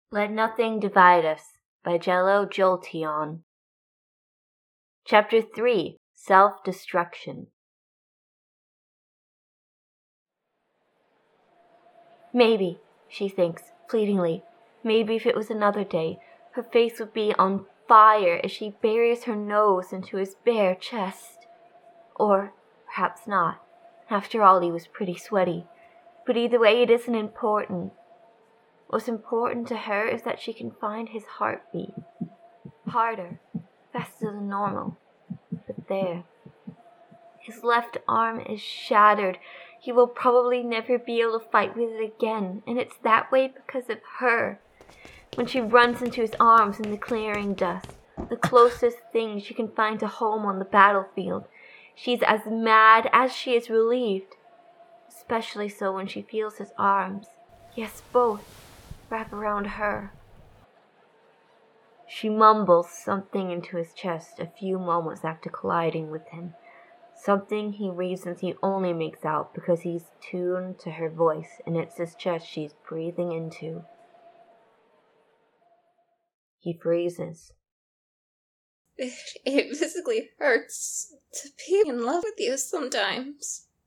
Let Nothing Divide Us : Chapter 3 - Self-Destruction | Podfic
Narrator Voice of Ochako Uraraka
Voice of Healer [OC]
Stirring in a cup of tea 1